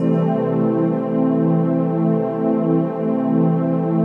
DM PAD2-23.wav